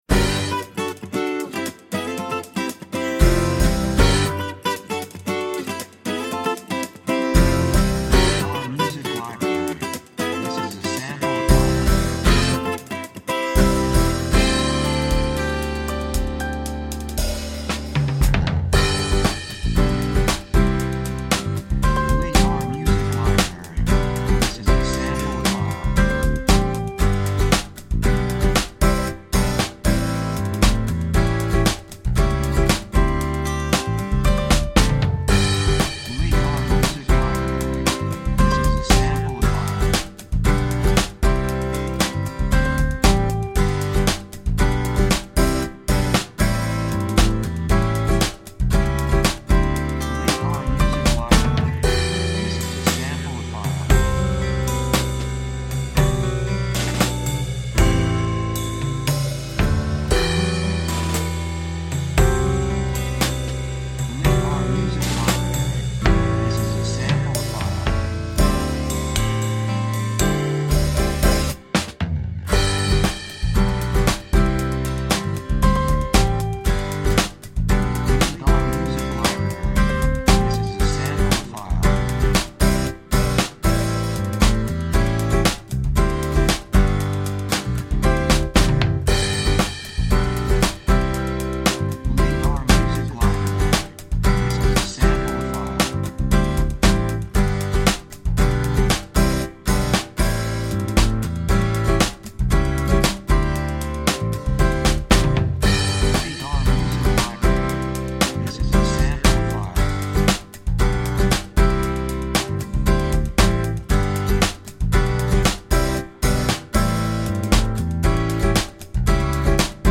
雰囲気幸せ, 穏やか, 高揚感, 感情的
楽器アコースティックギター, ピアノ
サブジャンルフォークポップ
テンポやや速い